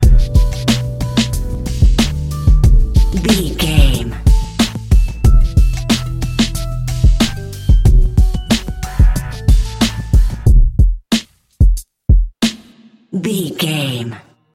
Aeolian/Minor
drum machine
synthesiser
electric piano
hip hop
soul
Funk
acid jazz
confident
energetic
bouncy
funky